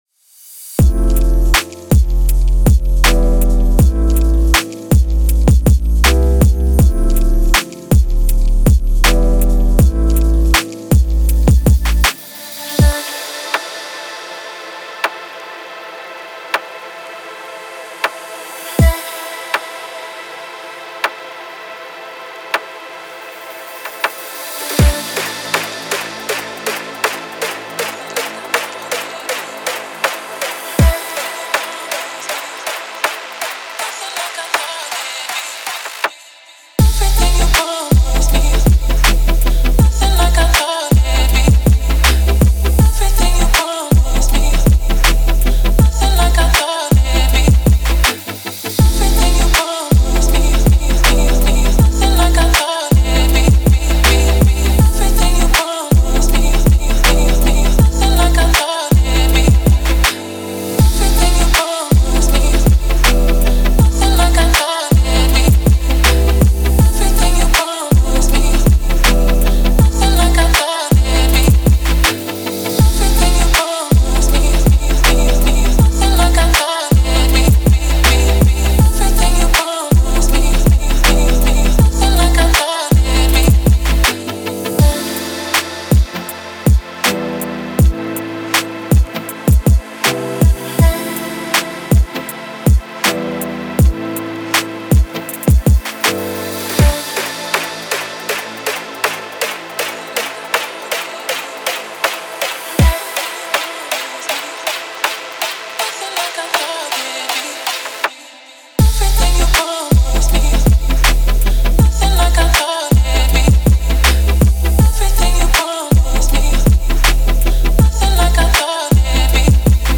это атмосферная трек в жанре электроники